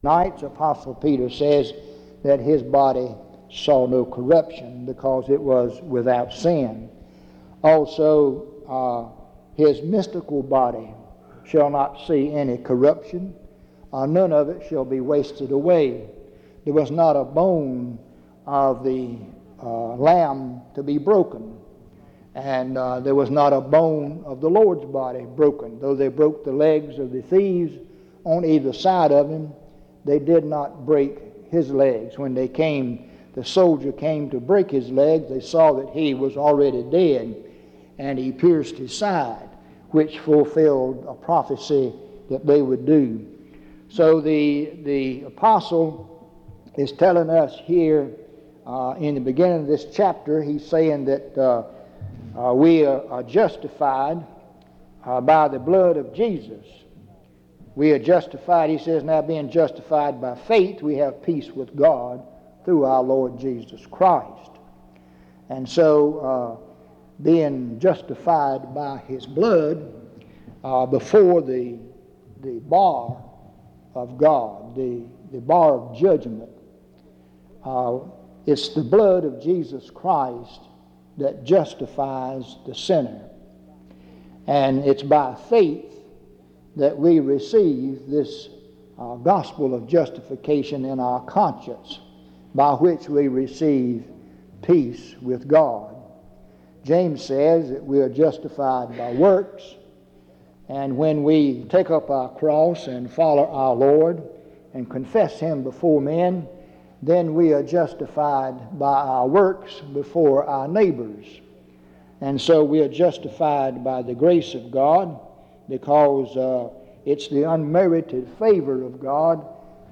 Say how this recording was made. En Collection: Reidsville/Lindsey Street Primitive Baptist Church audio recordings Miniatura Título Fecha de subida Visibilidad Acciones PBHLA-ACC.001_044-B-01.wav 2026-02-12 Descargar PBHLA-ACC.001_044-A-01.wav 2026-02-12 Descargar